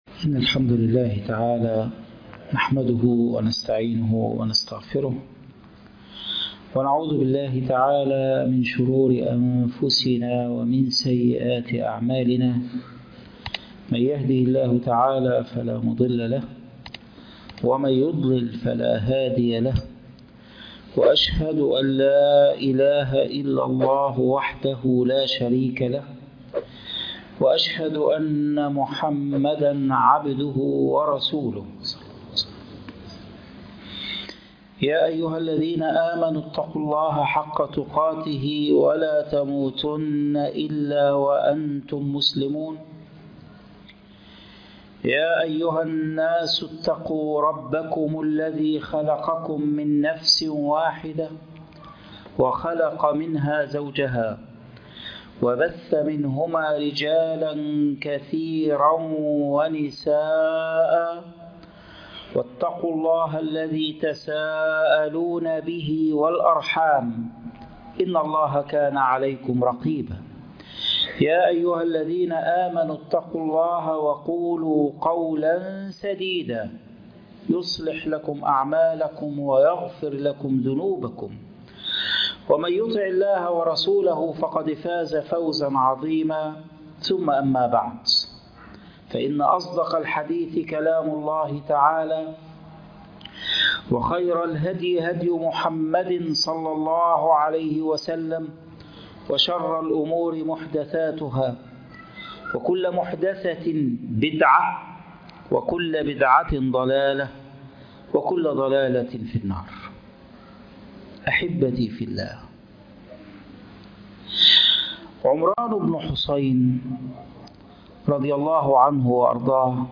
خطبة الجمعة